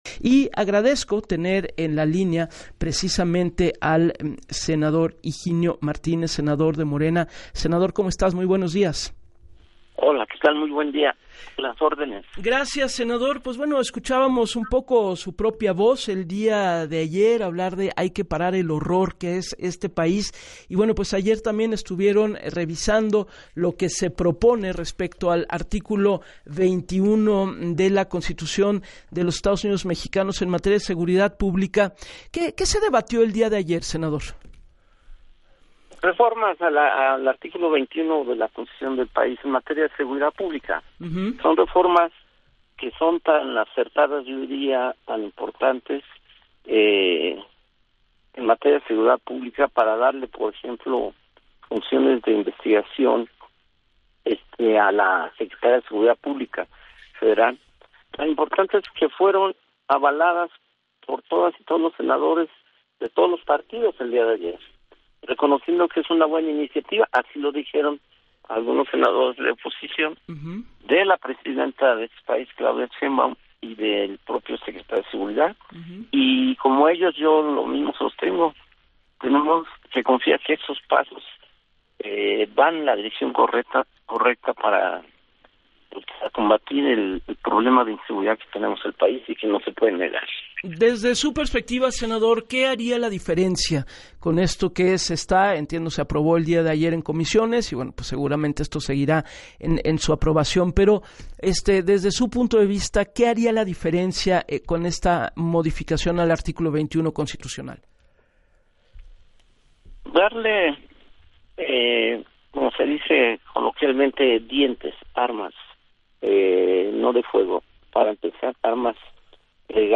En entrevista para “Así las Cosas” con Gabriela Warkentin, el senador celebró que el dictamen que reforma el artículo 21 de la Constitución haya sido avalado por todos los partidos.